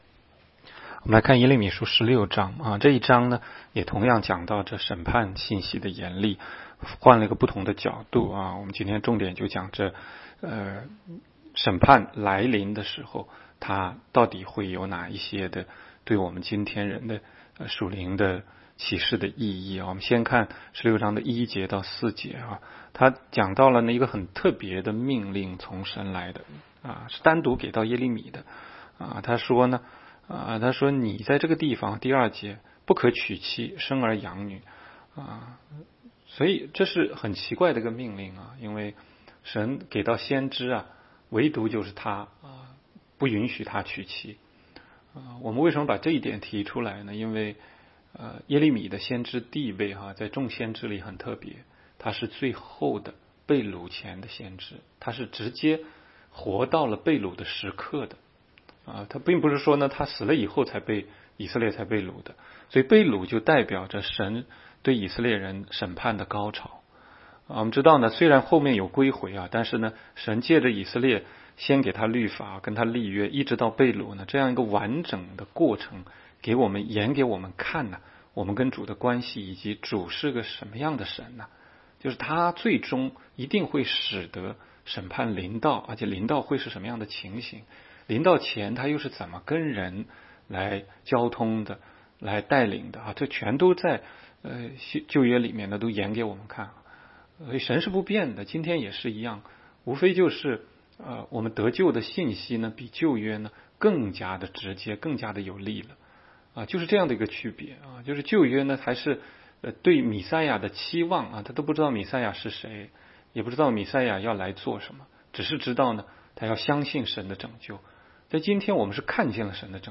16街讲道录音 - 每日读经 -《耶利米书》16章